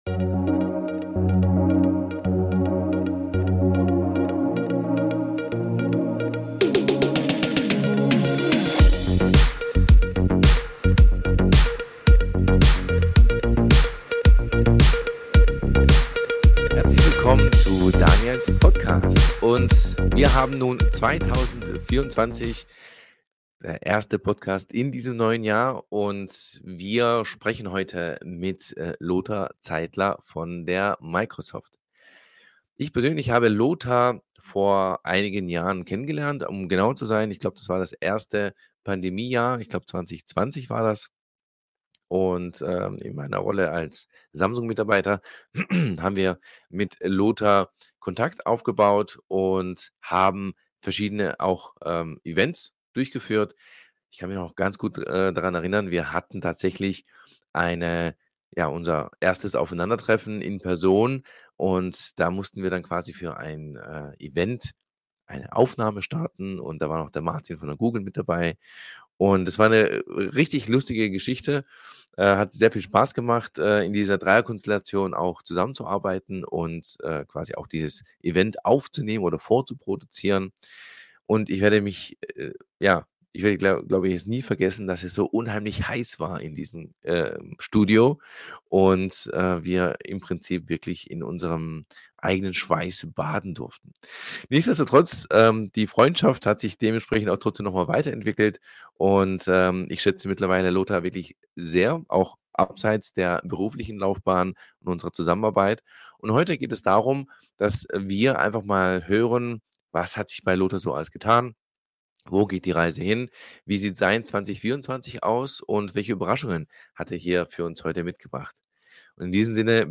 Seine jahrelange Erfahrung und Leidenschaft für Datenschutz machen ihn zu einem spannenden Gesprächspartner.